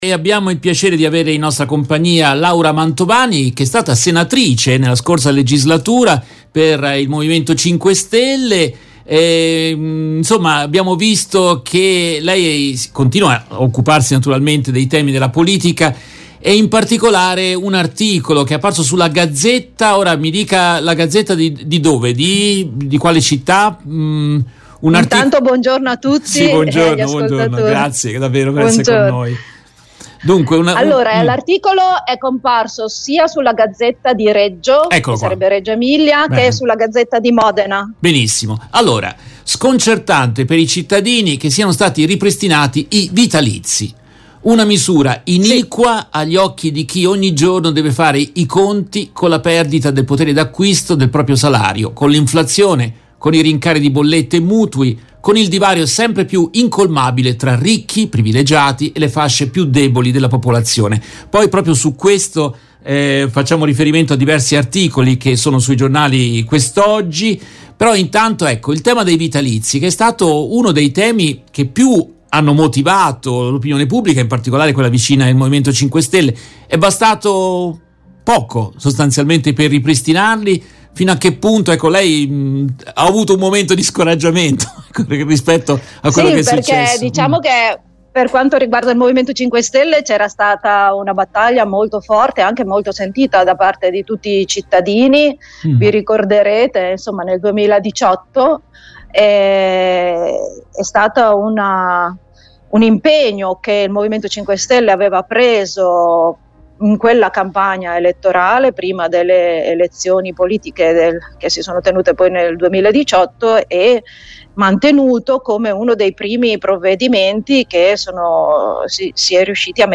Nel corso della diretta RVS del 12 luglio 2023